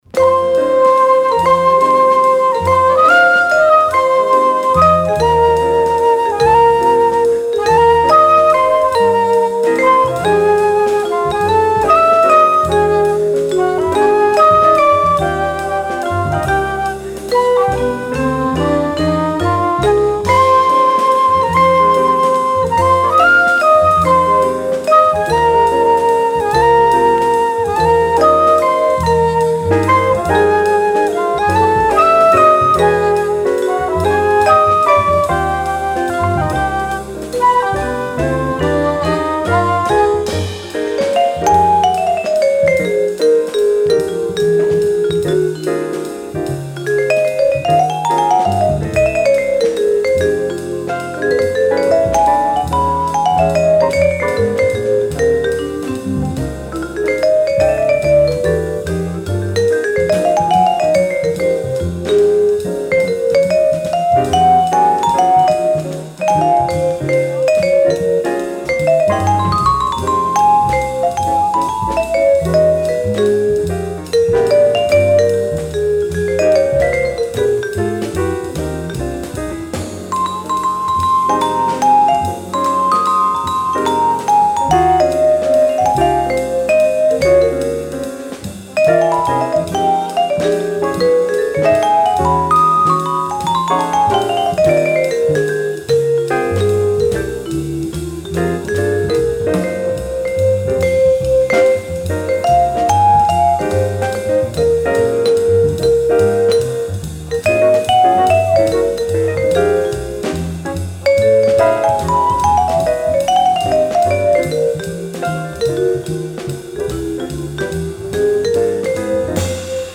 Original 1966 mono pressing